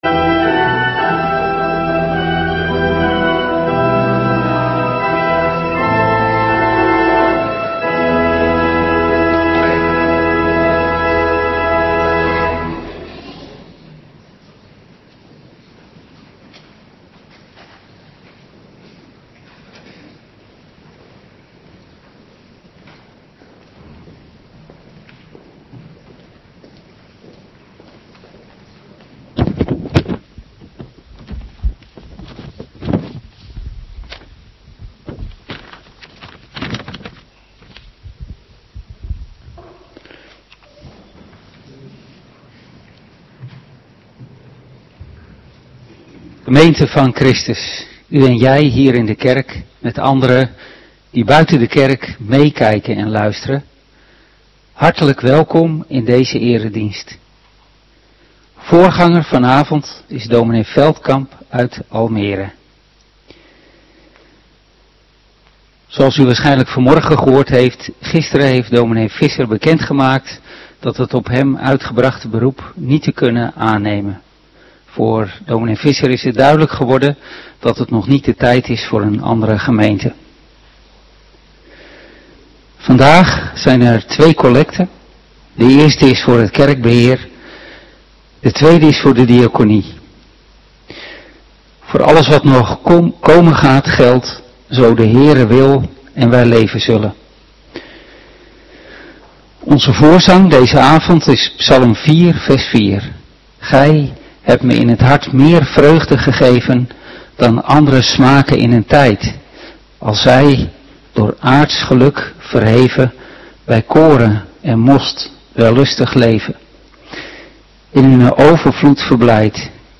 Avonddienst 10 augustus 2025